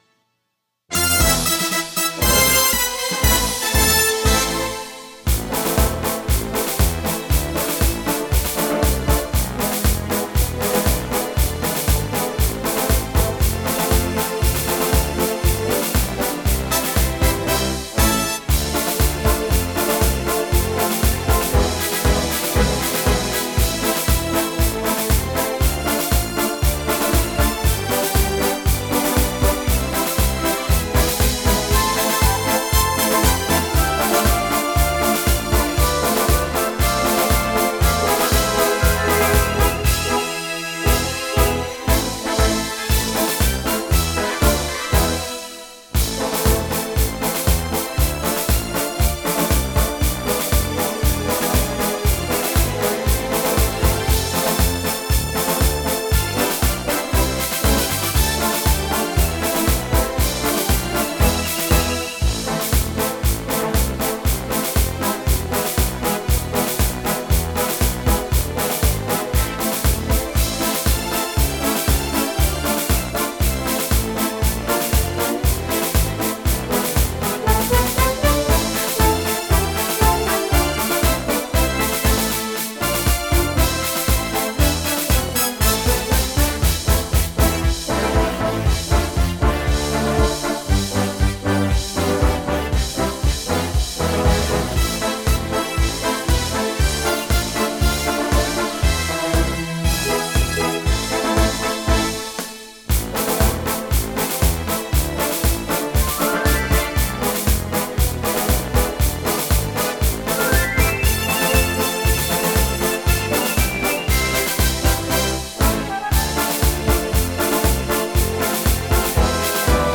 Главная / Песни для детей / Песни к 9 Мая
Слушать или скачать минус